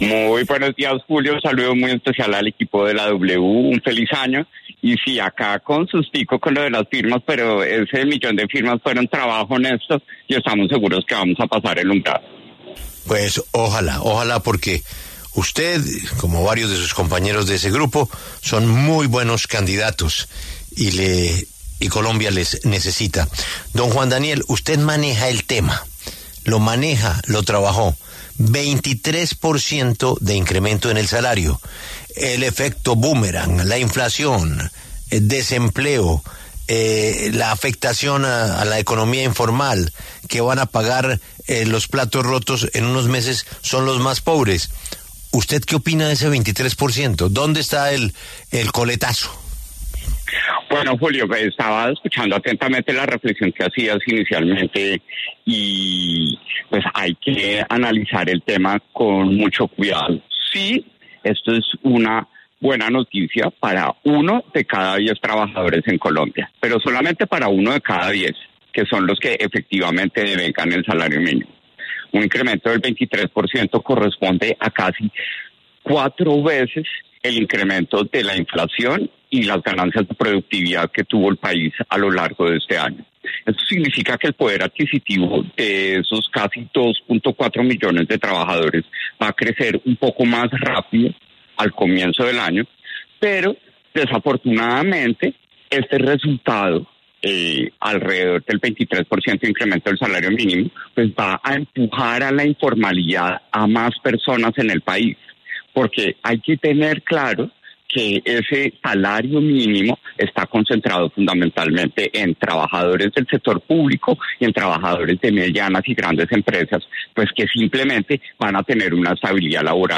Juan Daniel Oviedo, precandidato presidencial, conversó con La W a propósito del aumento del salario mínimo del 23.7% que anunció el presidente Gustavo Petro para el 2026 en Colombia.